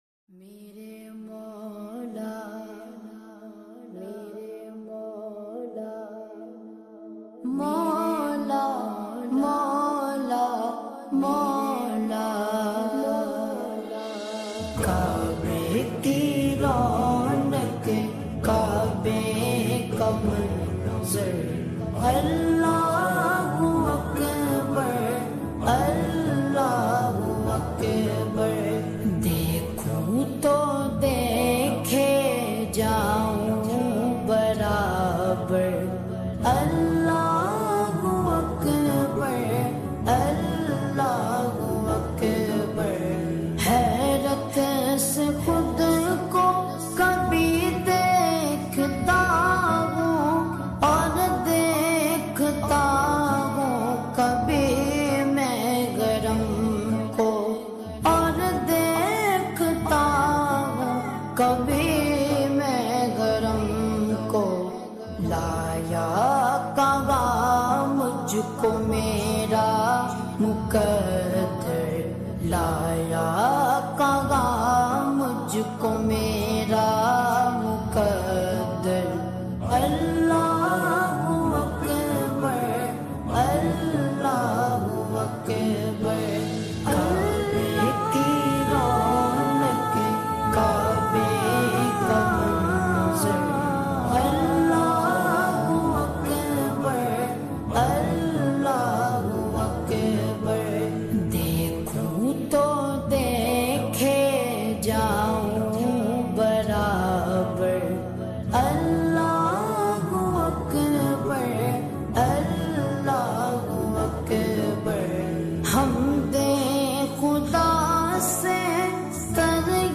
SLOWED AND REVERB
(SLOW+REVERB)